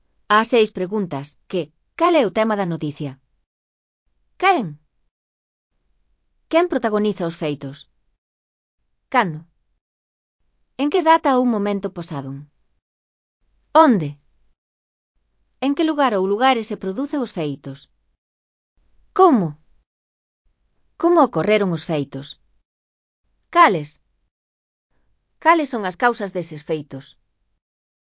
Elaboración propia (Proxecto cREAgal) con apoio de IA, voz sintética xerada co modelo Celtia. As 6 preguntas (CC BY-NC-SA)